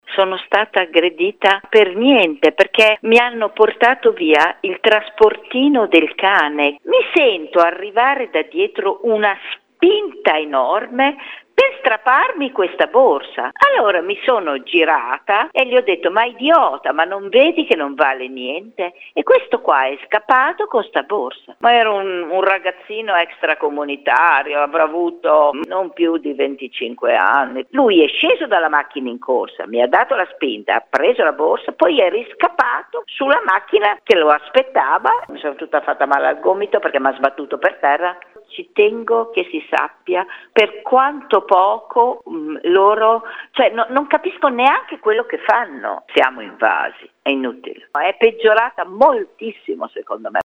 Ma sentiamo il suo racconto